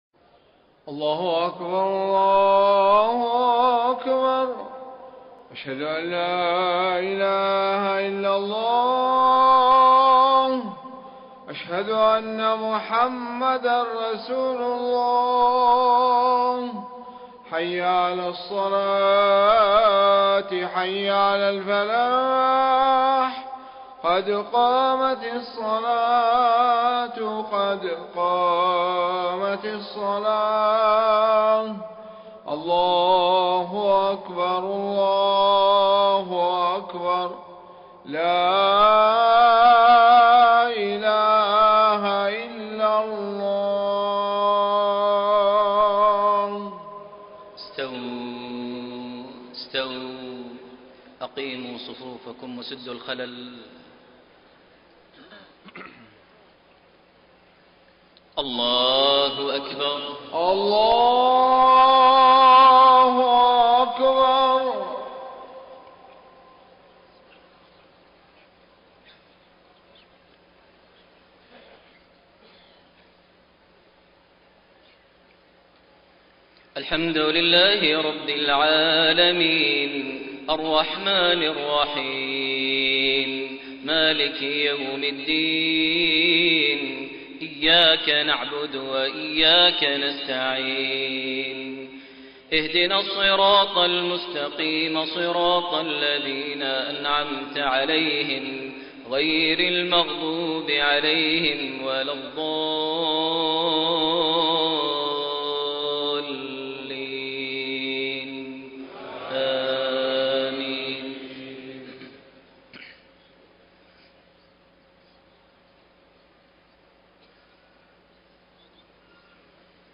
صلاة المغرب 20 جمادى الأولى 1433هـ خواتيم سورة التوبة 123-129 > 1433 هـ > الفروض - تلاوات ماهر المعيقلي